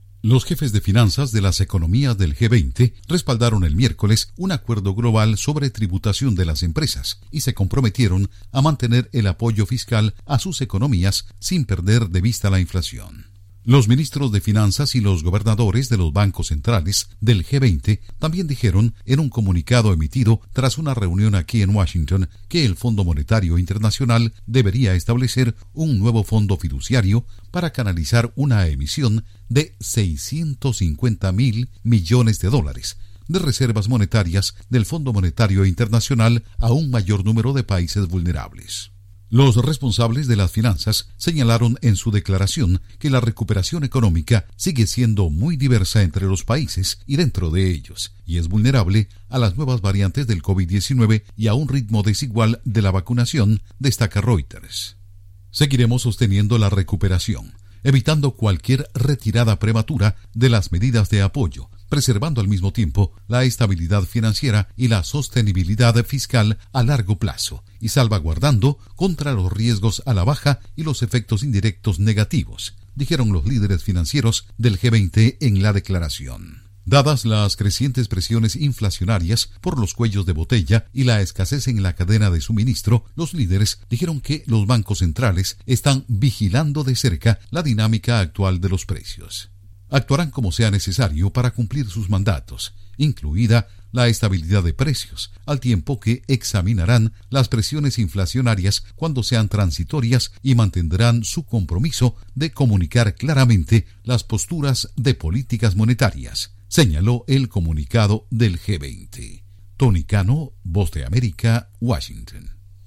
Jefes de Finanzas del G20 respaldan el acuerdo fiscal y se comprometen a vigilar la inflación. Informa desde la Voz de América en Washington